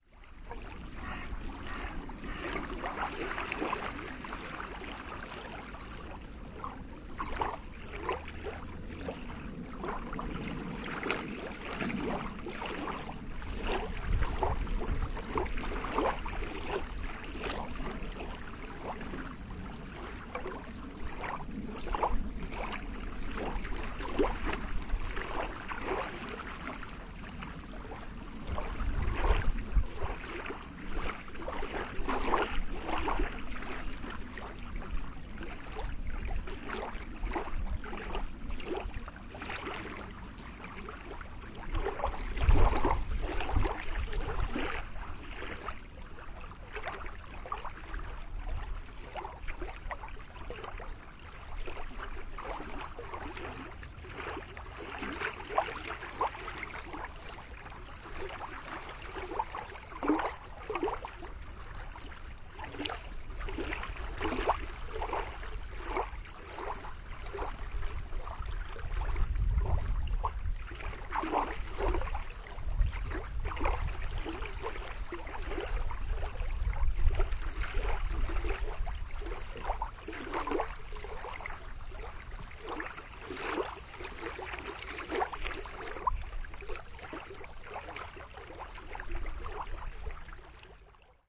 现场录音 " 风浪
描述：强烈的风浪吹来。在无叶的树木附近记录。用奥林巴斯LS10录制的。
标签： 场记录 波浪
声道立体声